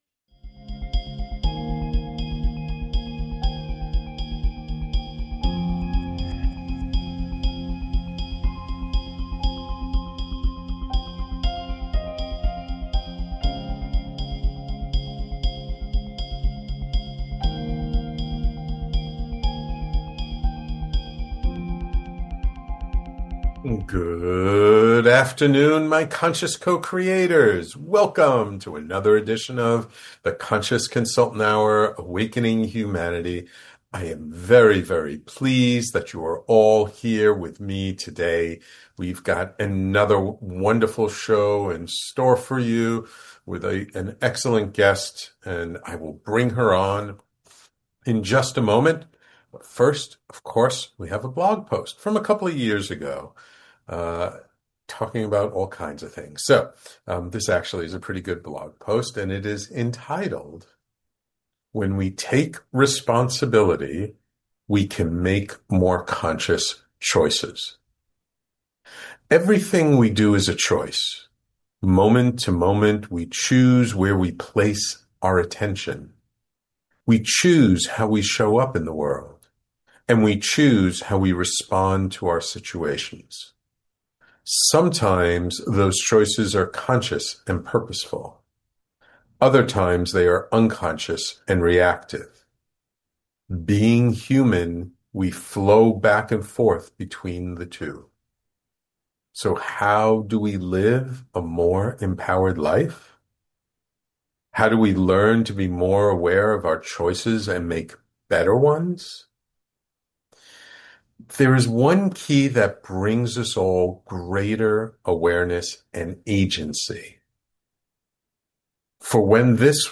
In this uplifting conversation